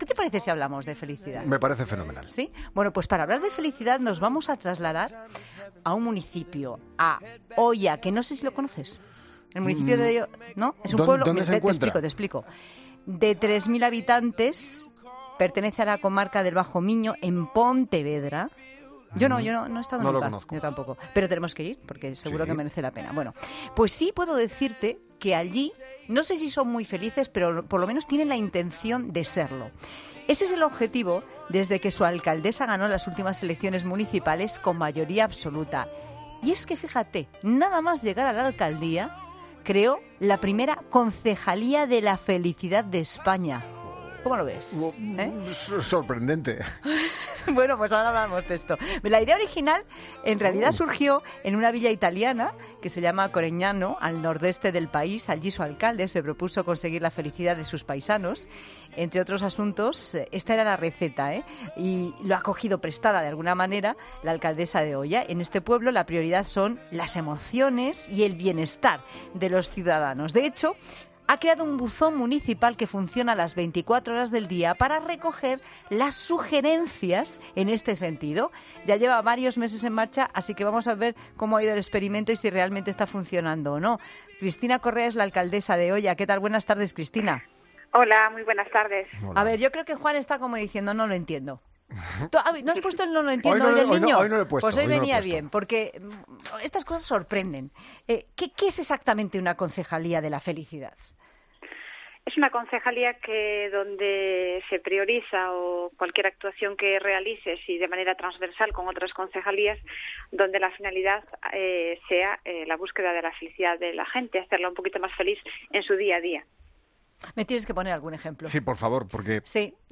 La alcaldesa de esta pequeña localidad gallega, Cristina Correa, cuenta en 'La Tarde de COPE' las competencias de esta insólita concejalía